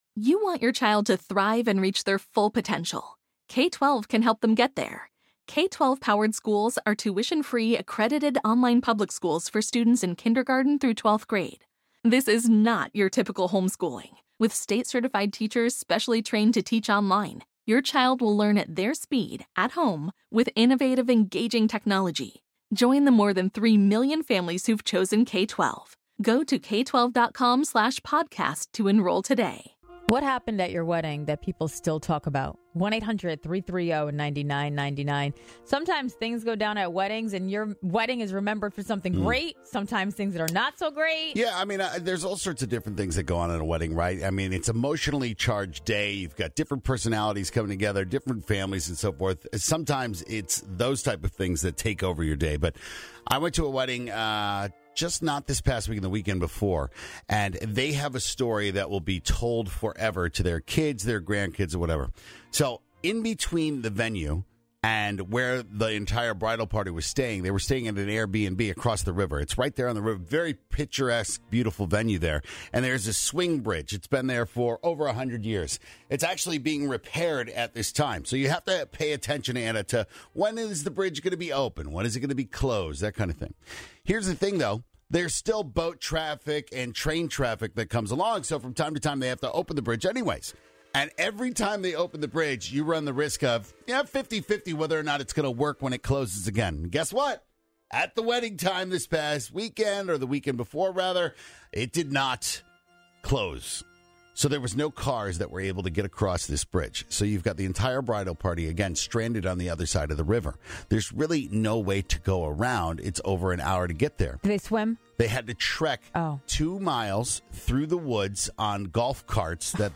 What happened at your wedding that no one will ever forget? If you missed the call from a woman that had the worst thing happen at her wedding, well, you don’t want to miss it.